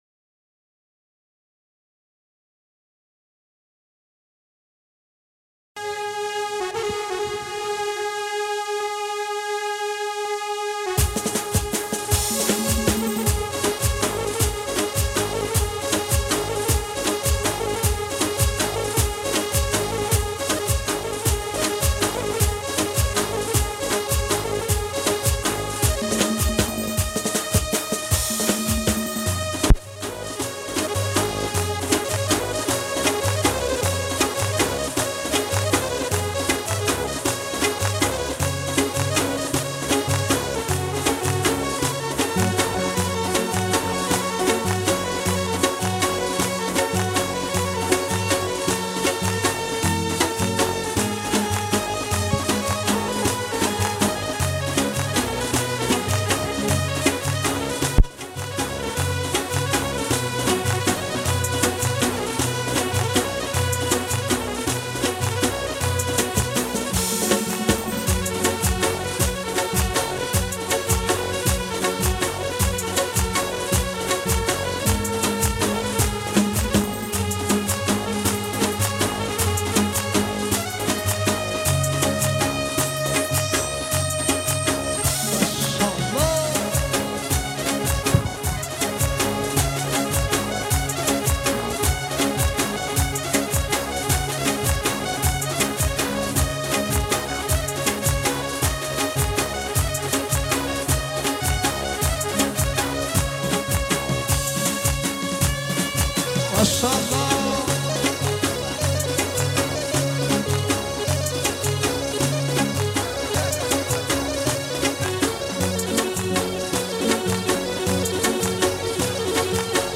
موسیقی کرمانجی